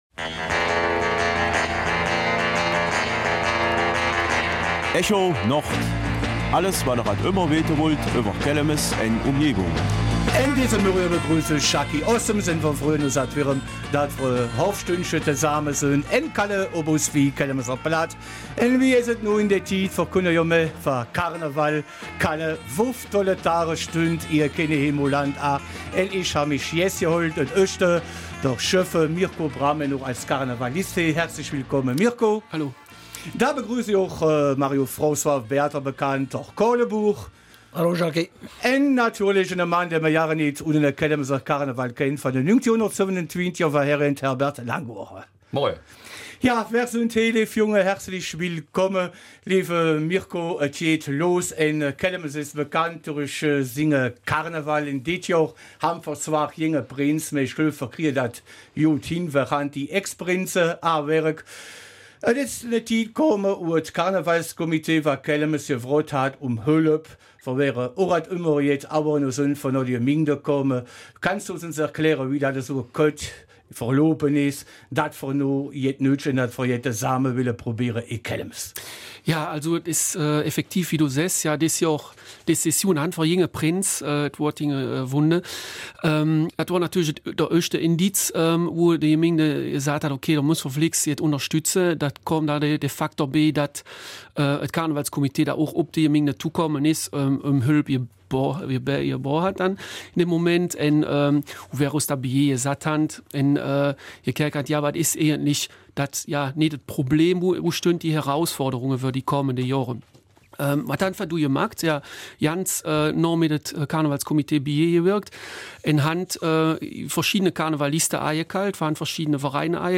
Kelmiser Mundart: Kenehemo-Karneval im Umbruch